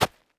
SAND.3.wav